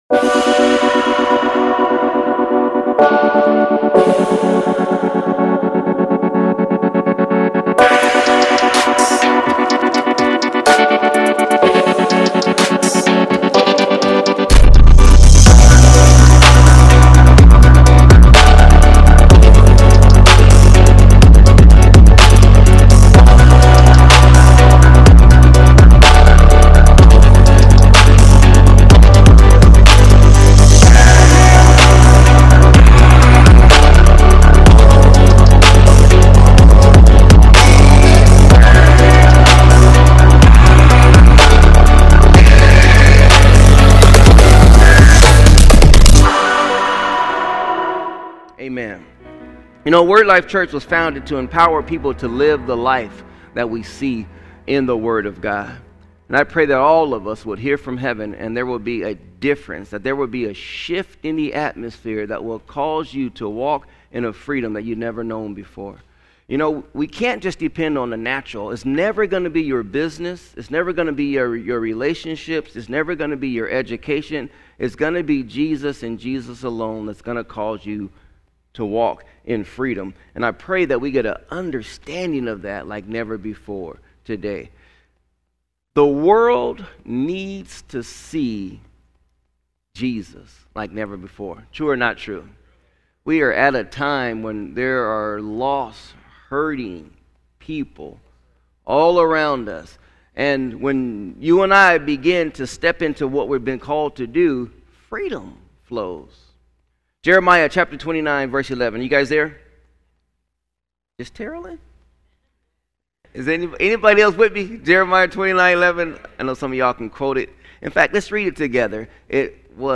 Sermons | Word Life Church